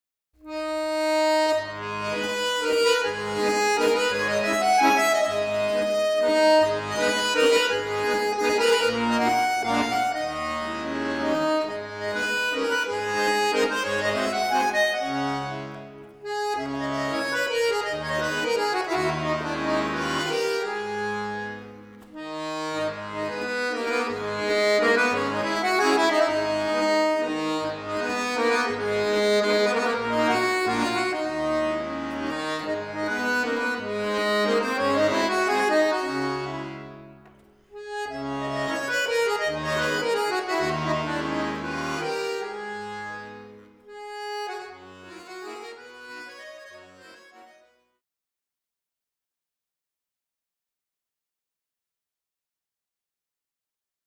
Akkordeon
55-Handarmonika-Akkordeon.mp3